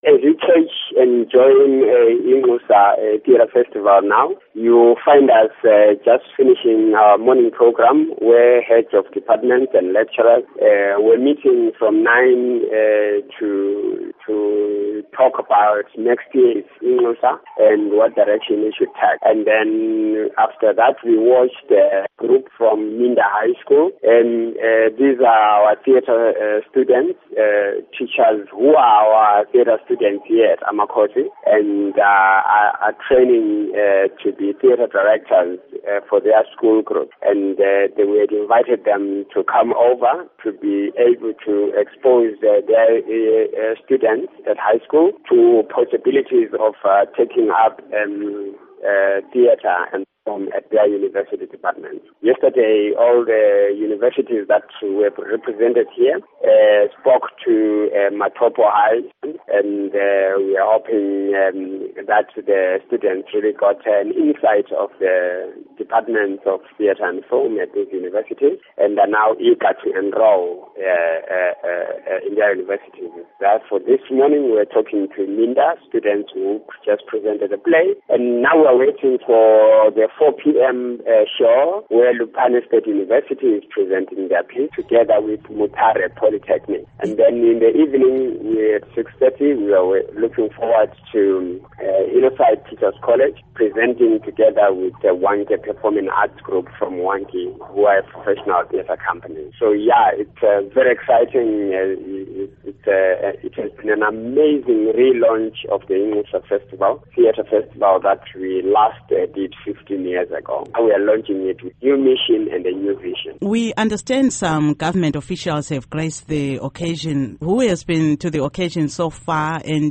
Interview with Cont Mhlanga